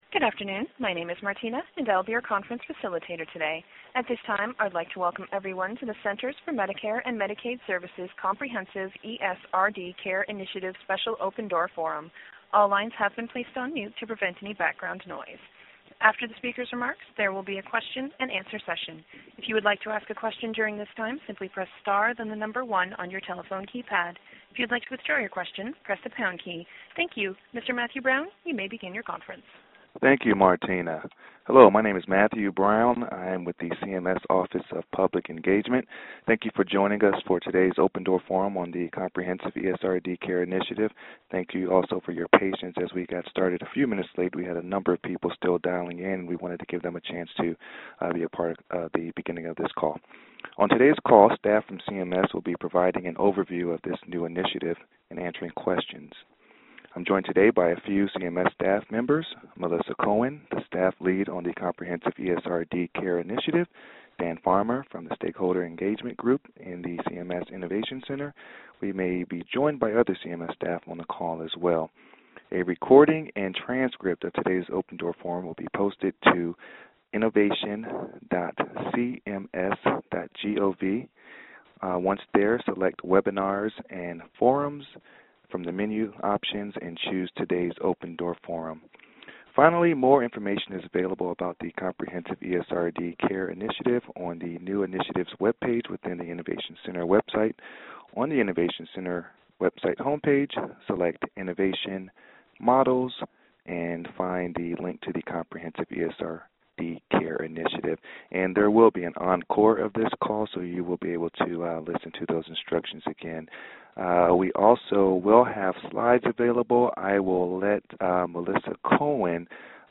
When: Tuesday, February 5, 2013 | 1:00 - 2:30pm ET On February 4, 2013, CMS announced the new Comprehensive ESRD Care Initiative. On this Open Door Forum, CMS staff provided an overview of the initiative and answered questions from interested stakeholders.